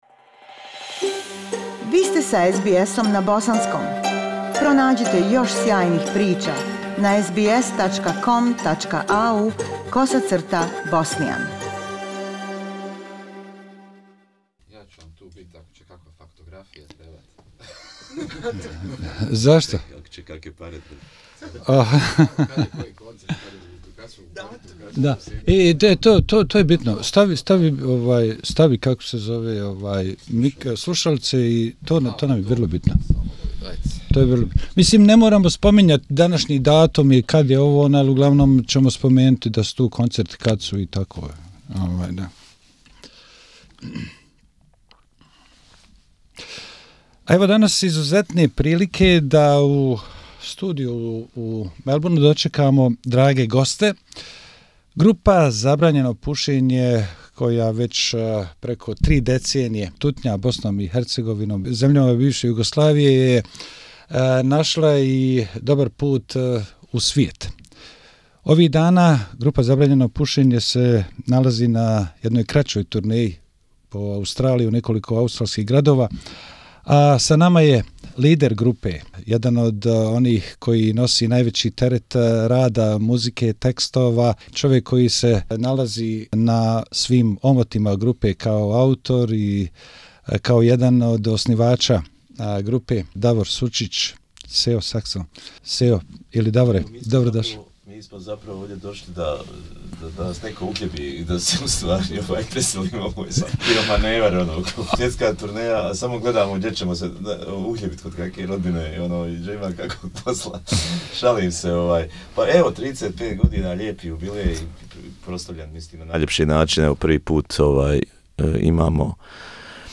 Bosnian band Zabranjeno Pušenje leader, Davor Sucic (Sejo Sexon) was a guest at our studio and spoke about 35 years of the release of their first album... how is he seeing his band, concerts, Bosnia and Herzegovina today.